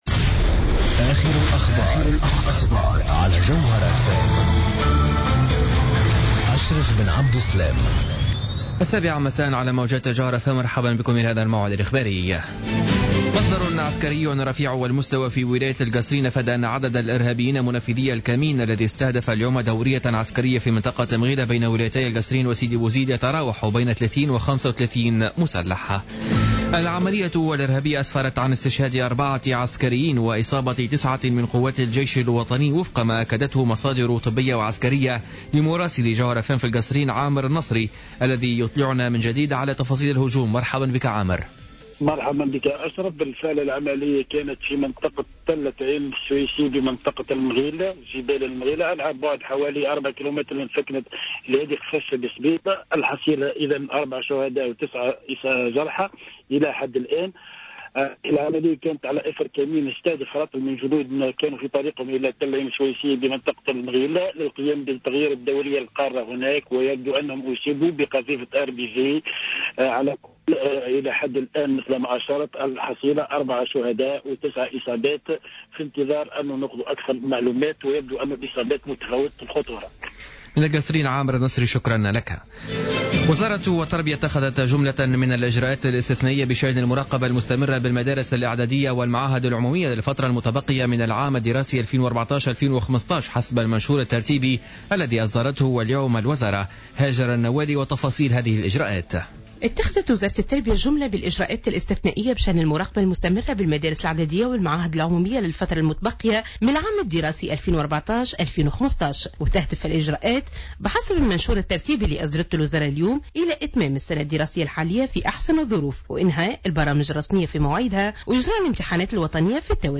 نشرة أخبار السابعة مساء ليوم الثلاثاء 7 أفريل 2015